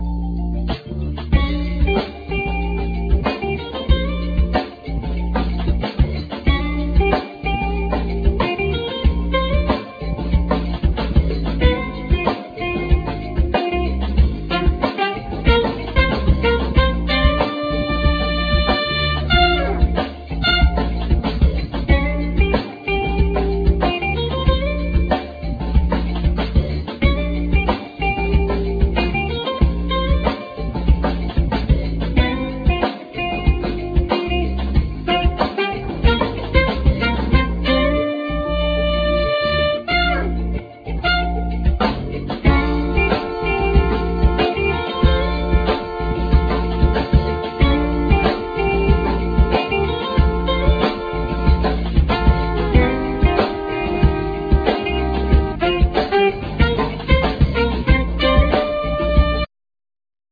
Vocal,Percussions
Alt sax
Drums,Samples
Guitar
Double bass
Trumpet